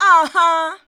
AHA 1.wav